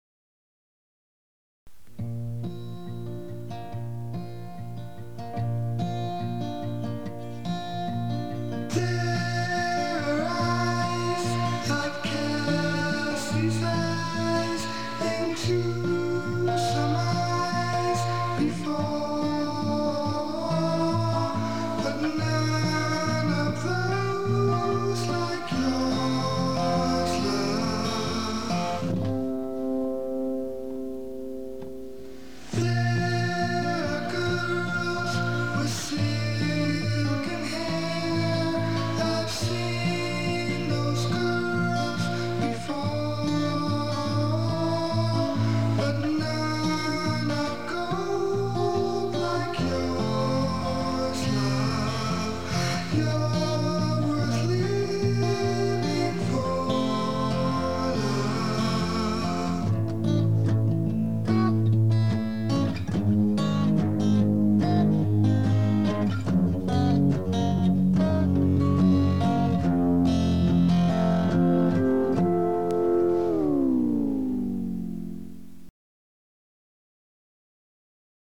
Pour les amateurs de folk